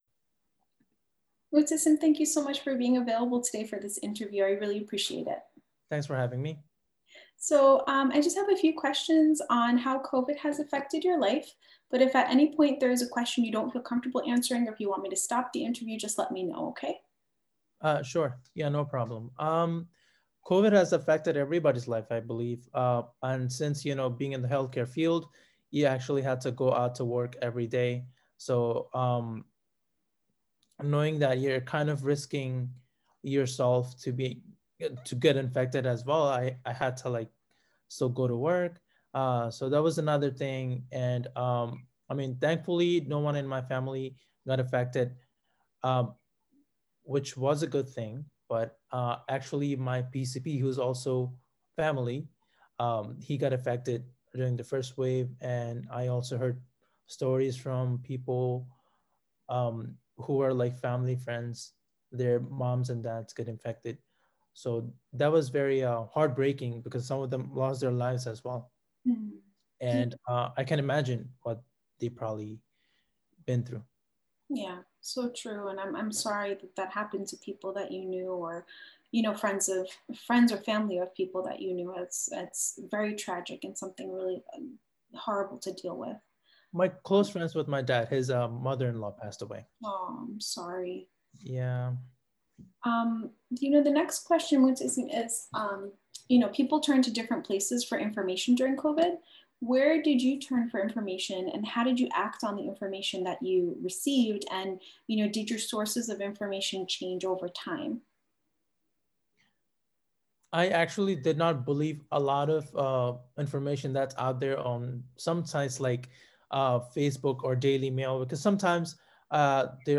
Participant 446 Community Conversations Interview